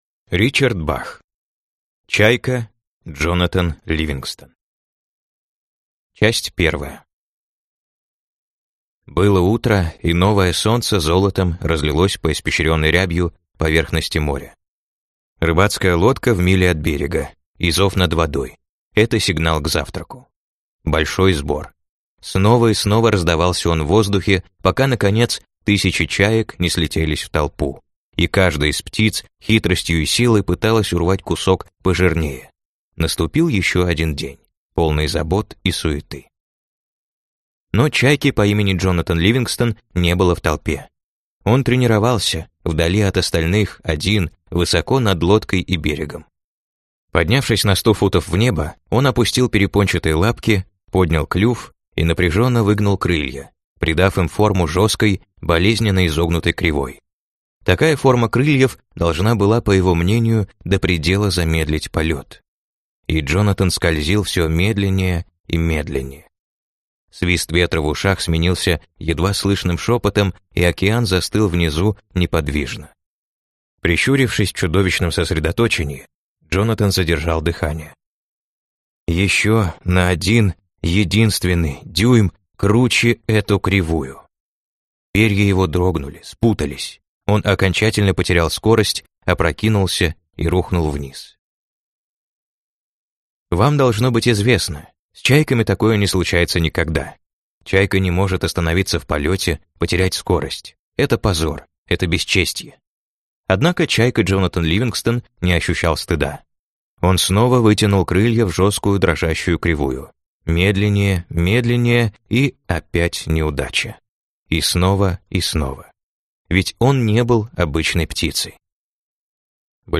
Аудиокнига Чайка Джонатан Ливингстон | Библиотека аудиокниг